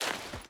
Footsteps / Dirt / Dirt Jump.wav
Dirt Jump.wav